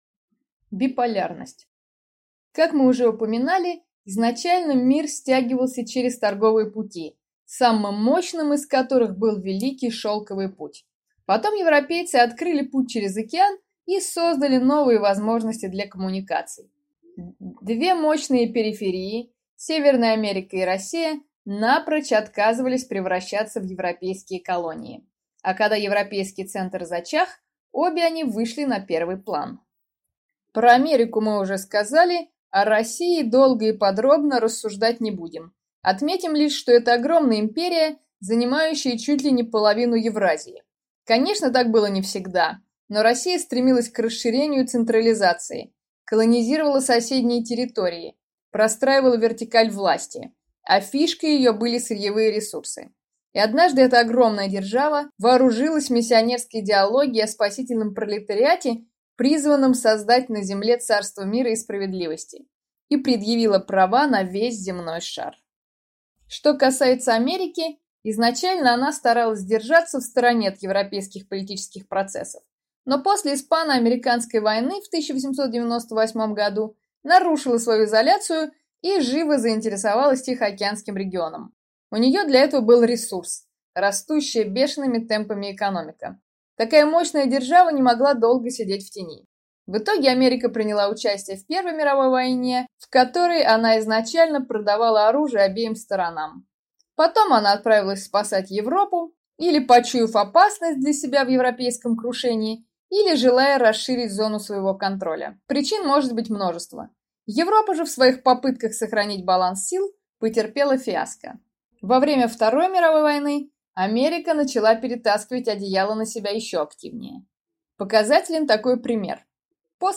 Аудиокнига Биполярный мир | Библиотека аудиокниг